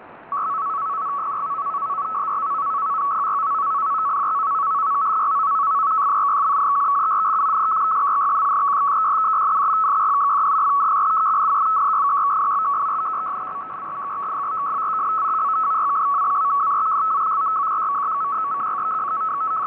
PACTOR FEC (308kB) / PACTOR-2 ARQ (196kB) / PACTOR-2 FEC (98kB)
PACTOR_FEC.wav